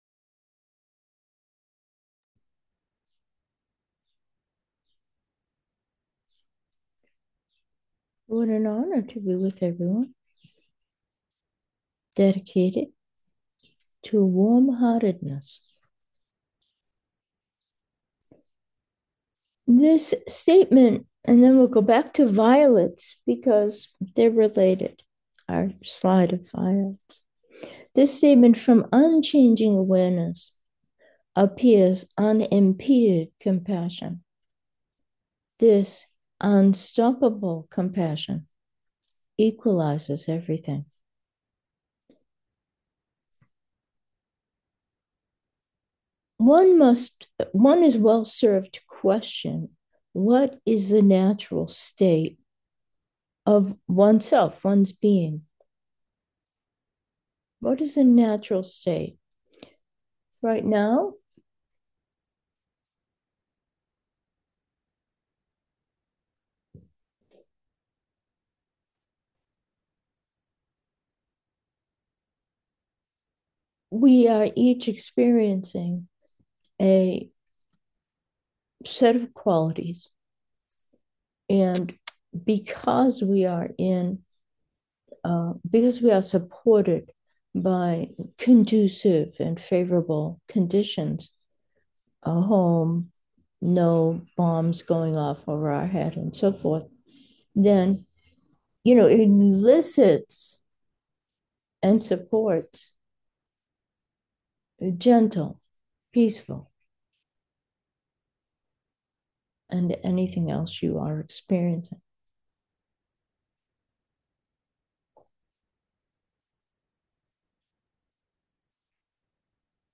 Meditation: natural, awareness